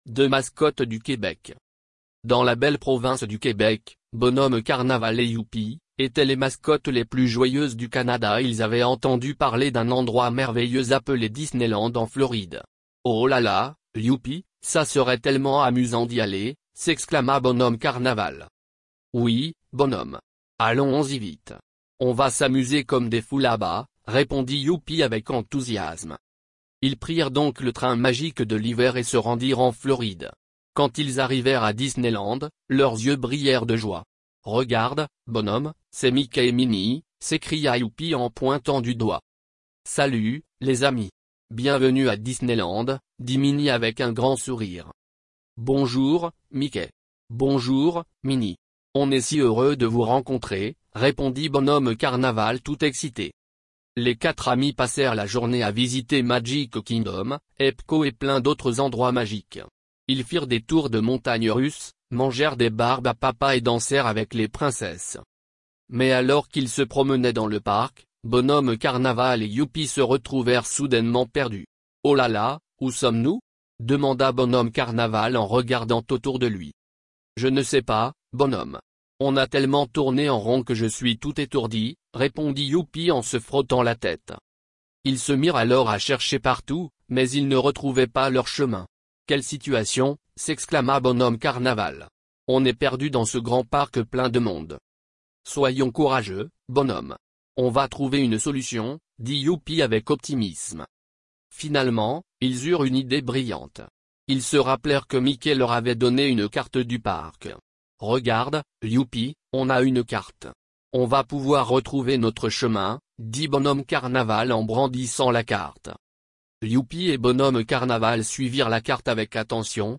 Lectireorale.mp3